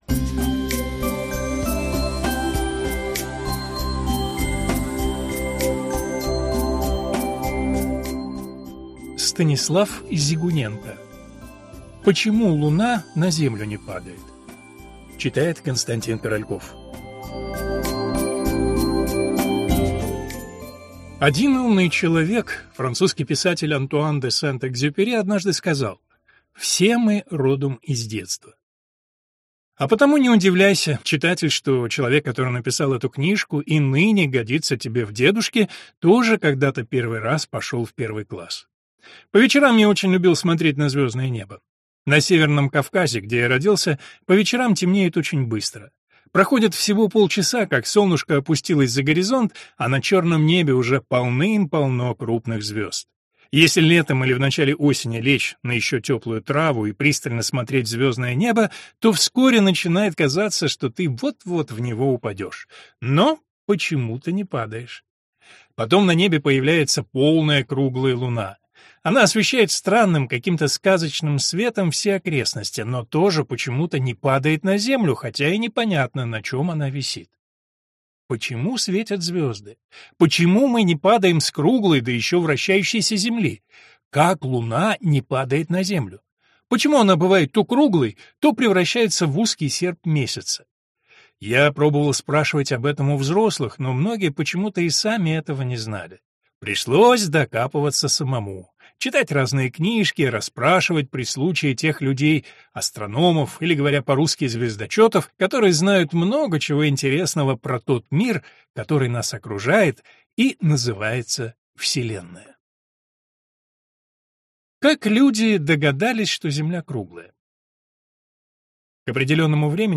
Аудиокнига Почему Луна на землю не падает?
Прослушать и бесплатно скачать фрагмент аудиокниги